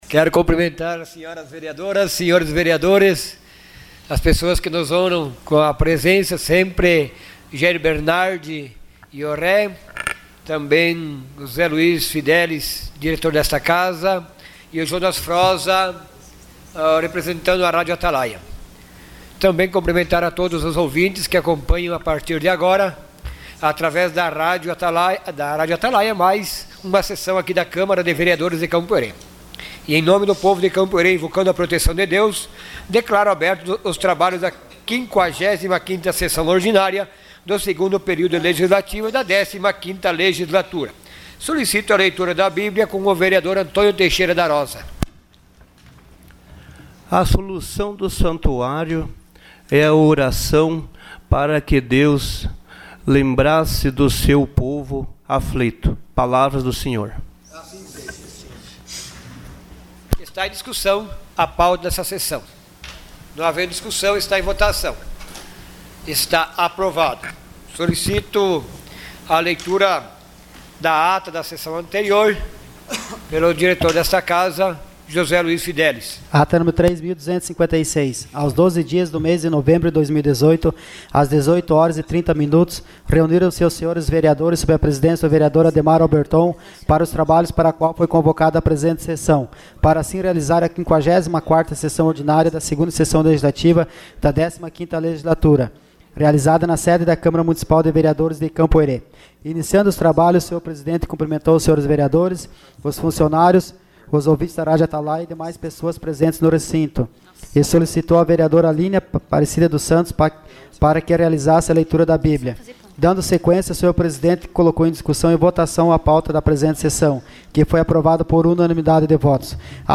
Sessão Ordinária dia 19 de novembro de 2018.